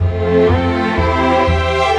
Rock-Pop 11 Strings 02.wav